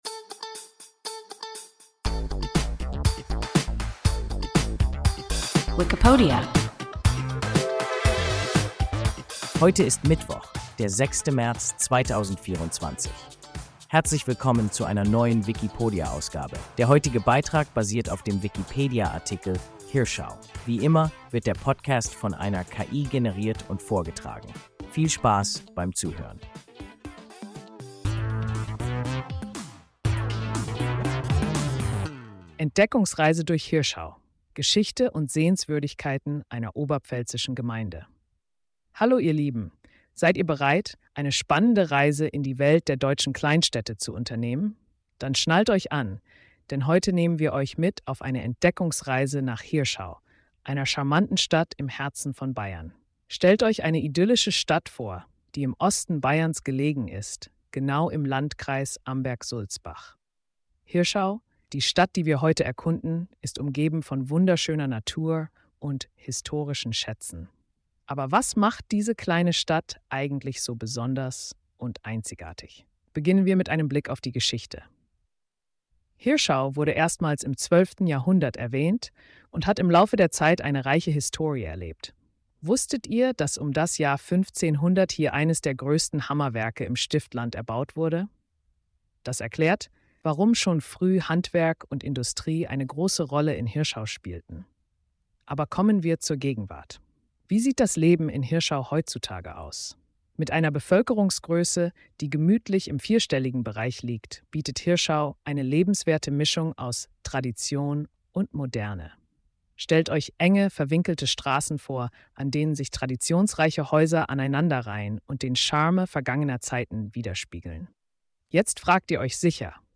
Hirschau – WIKIPODIA – ein KI Podcast